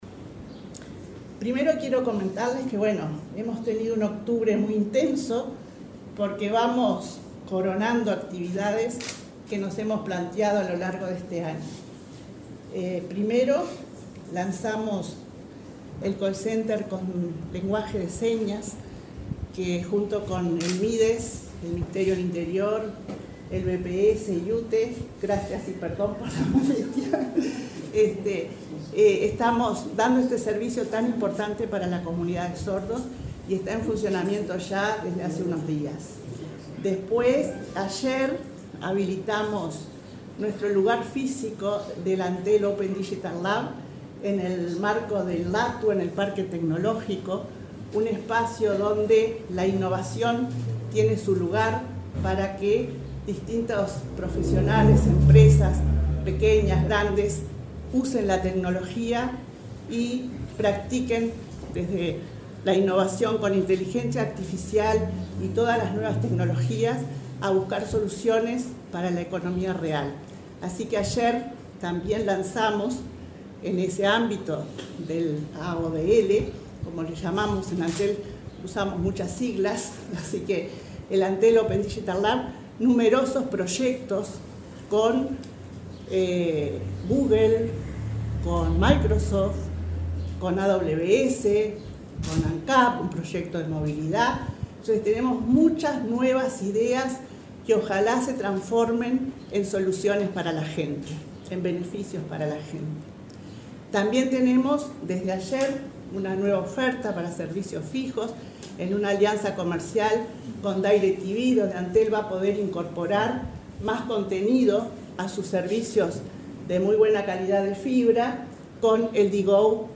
Palabras de la presidenta de Antel, Annabela Suburú